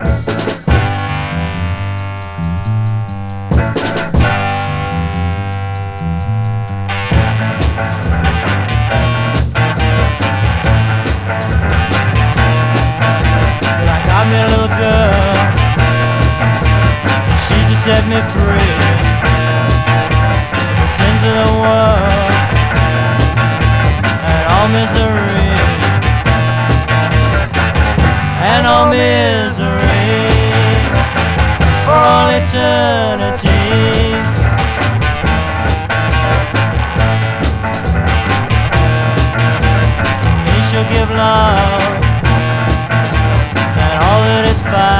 盤面から溢れる猛る音、それぞれに気合いが漲る様が痛快とも思える心地良さ。
Rock, Garage, Psychedelic　France　12inchレコード　33rpm　Mono